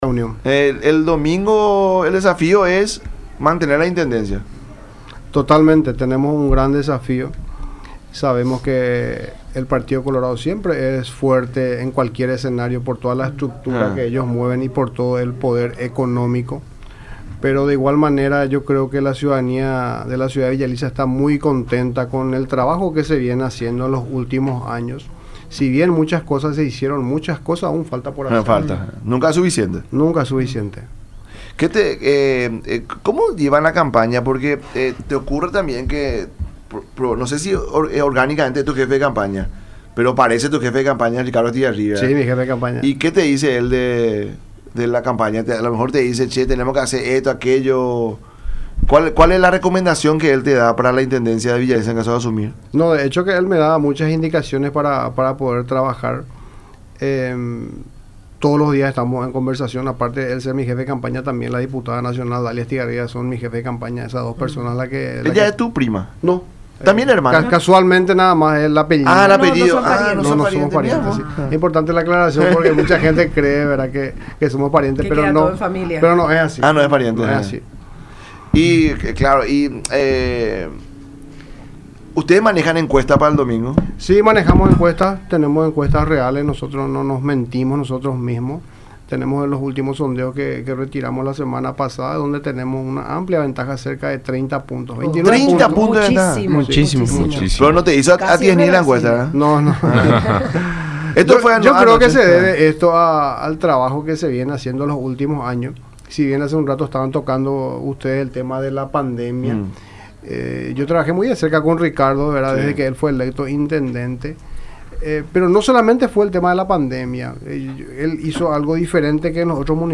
En su visita al programa La Mañana de Unión, a través de radio La Unión y Unión TV, explicó que existe un ambiente muy tranquilo a lo que pueda suceder este domingo.